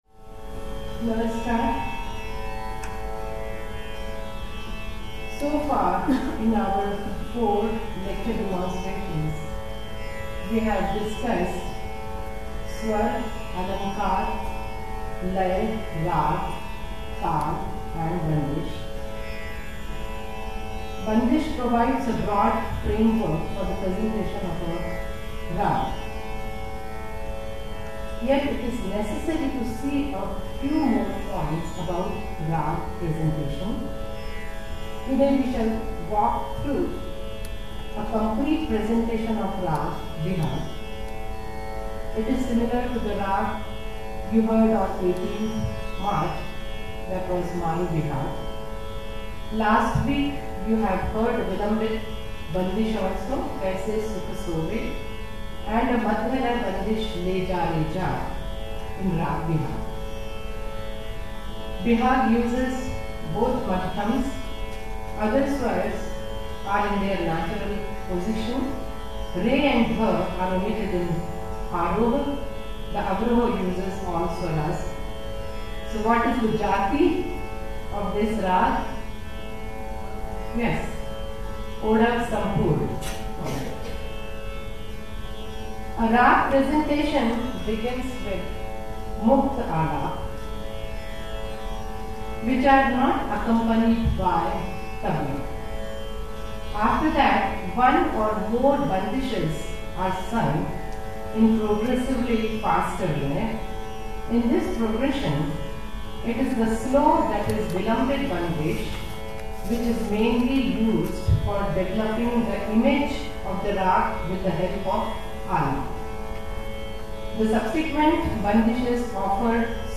Narration
Tabla
and violin
Tanpura
Lecture - demonstrations Appreciation of Khyal